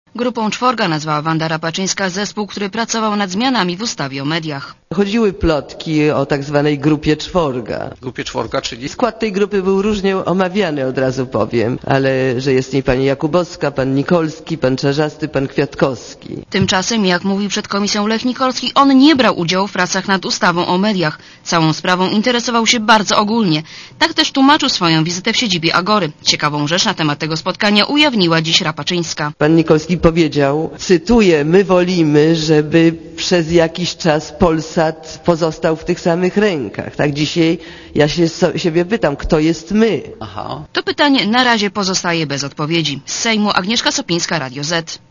Posłuchaj relacji reportera Radia Zet (176Kb)